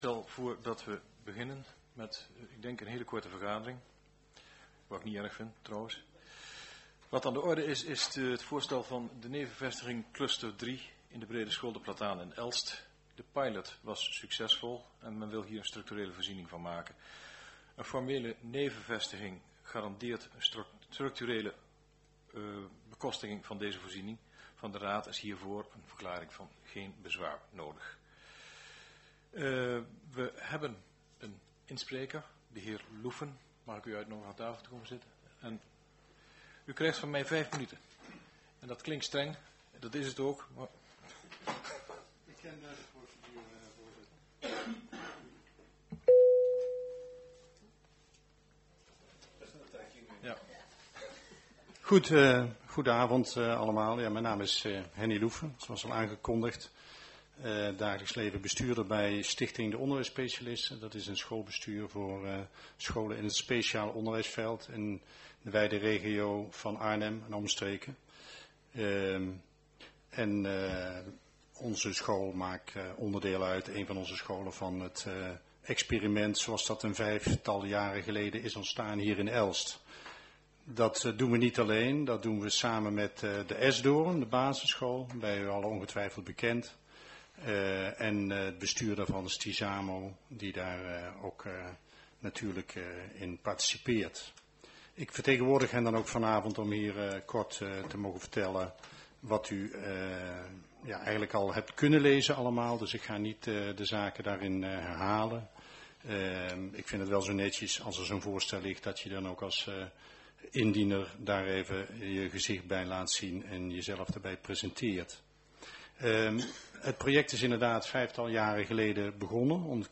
Locatie gemeentehuis Elst
Voorbereidende vergadering Verklaring van geen bezwaar voor nevenvestiging Lichtenbeek in brede school De Plataan in Elst